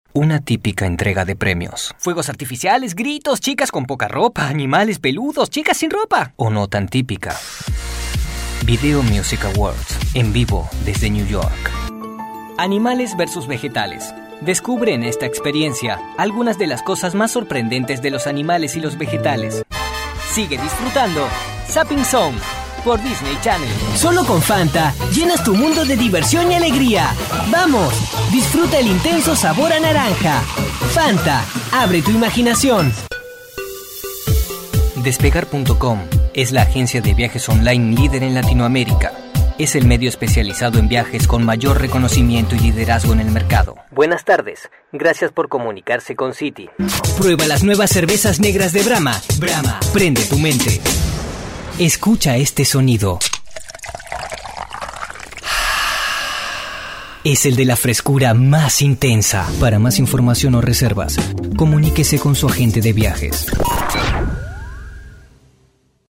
voz joven , actor , doblajes , voz dinamica ideal para comerciales,
spanisch Südamerika
Sprechprobe: Werbung (Muttersprache):